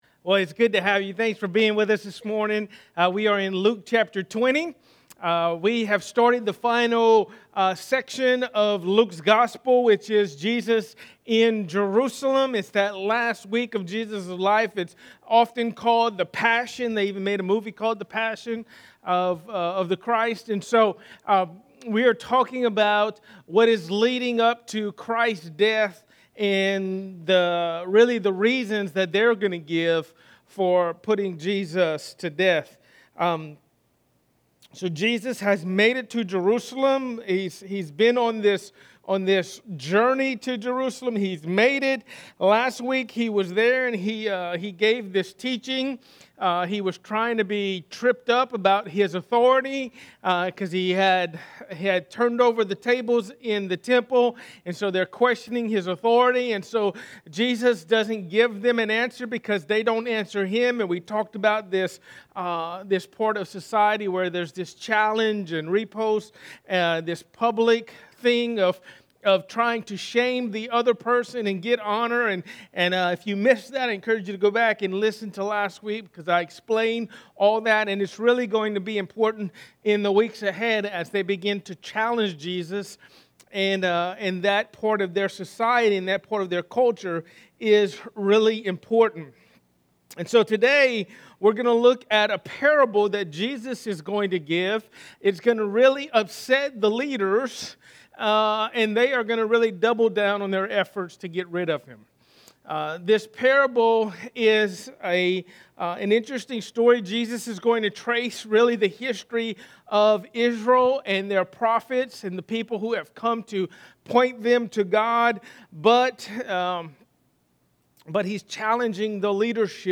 A message from the series "Luke." Jesus tells of Israel's history of rejection.